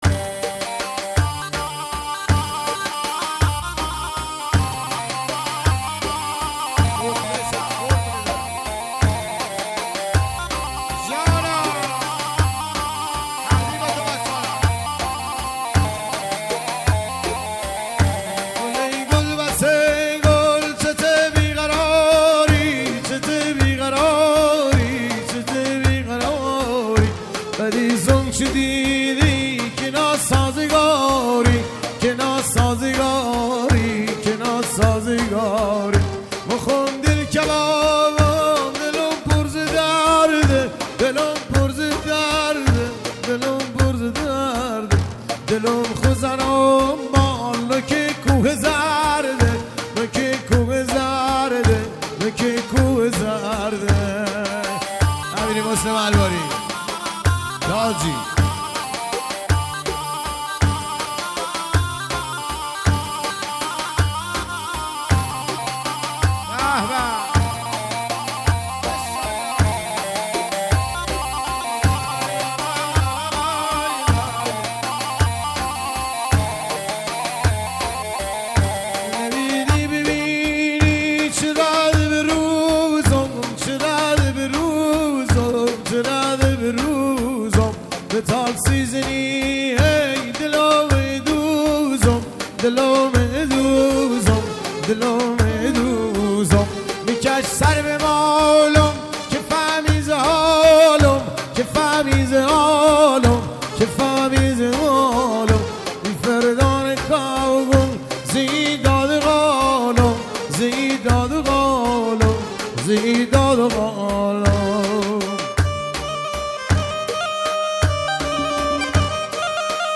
محلی لری